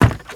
STEPS Wood, Creaky, Run 06.wav